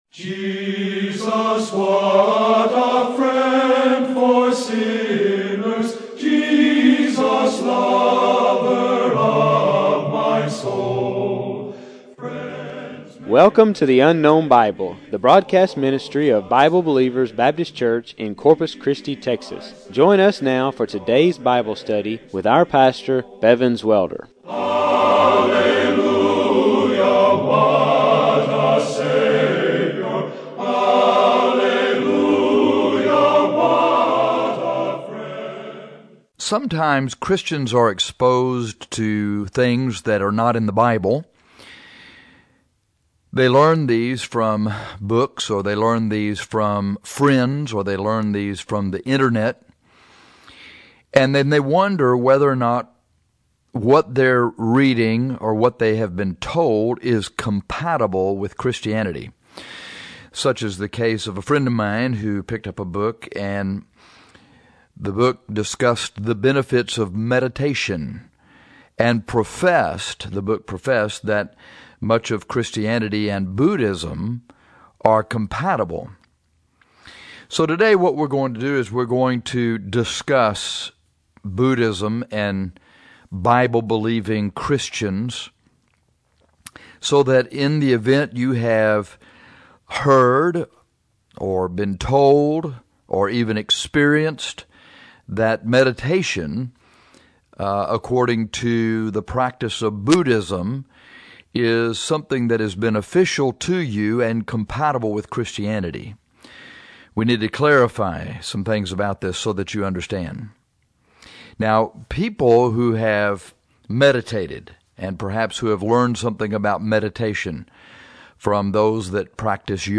This broadcast is is a comparison of the Bible versus Buddhism.